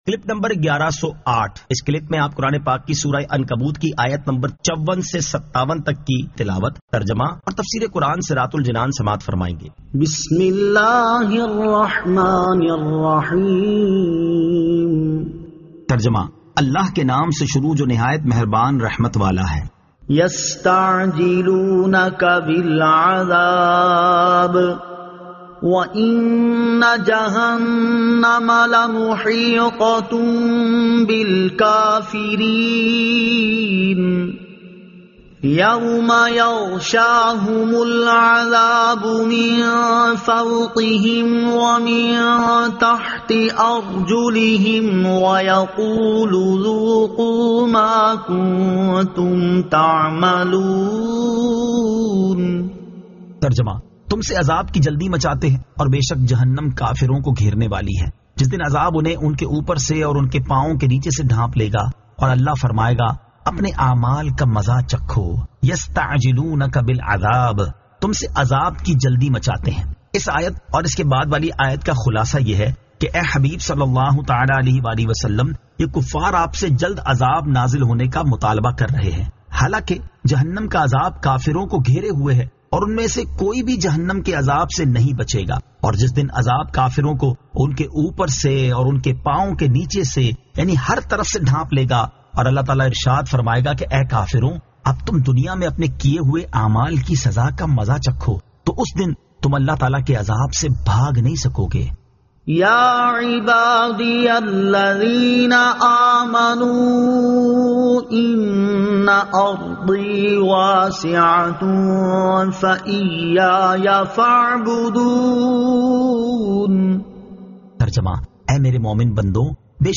Surah Al-Ankabut 54 To 57 Tilawat , Tarjama , Tafseer